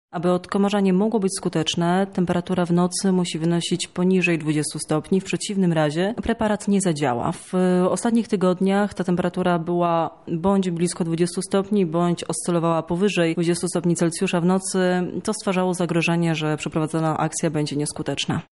O tym, dlaczego zmieniono termin akcji odkomarzania mówi